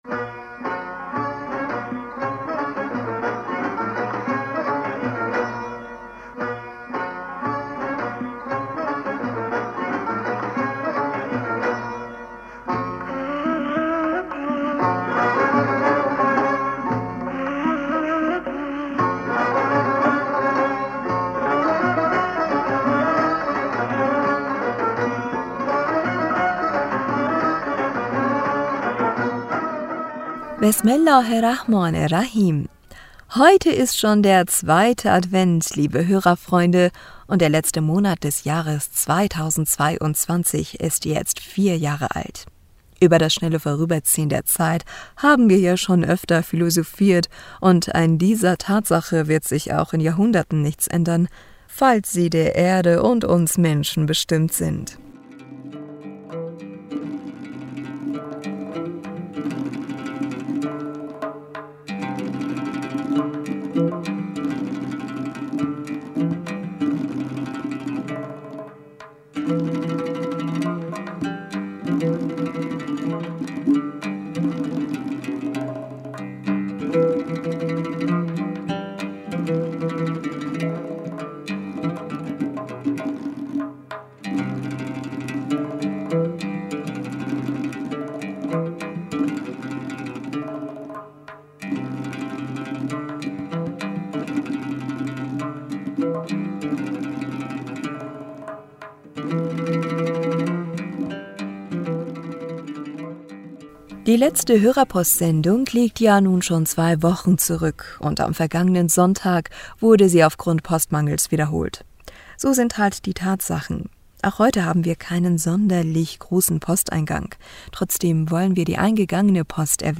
Hörerpostsendung am 04. Dezember 2022 Bismillaher rahmaner rahim.Heute ist schon der 2.